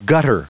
Prononciation du mot gutter en anglais (fichier audio)
Prononciation du mot : gutter